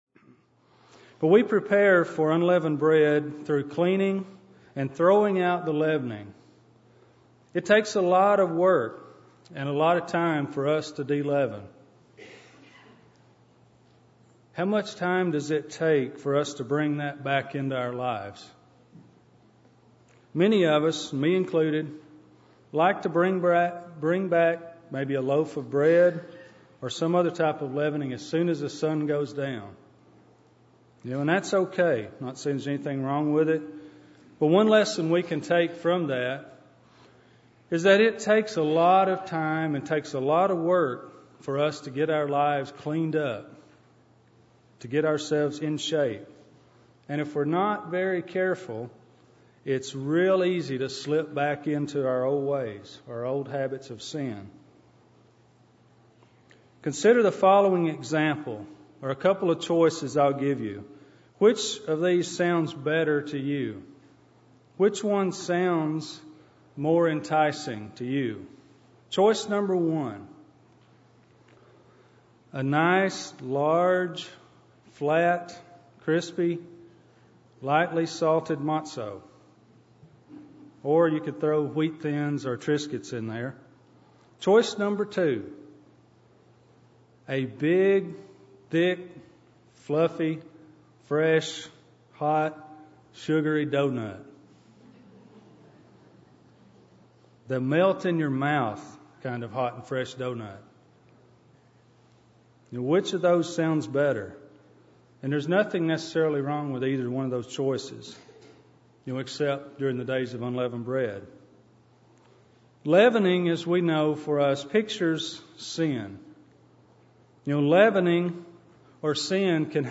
This sermon was given on the Last Day of Unleavened Bread.
Given in Nashville, TN